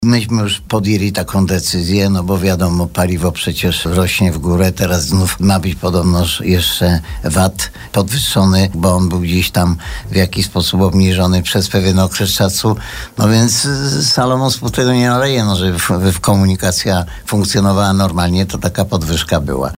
O podwyżkach cen biletów rozmawialiśmy kilka dni temu z burmistrzem Żywca, Antonim Szlagorem.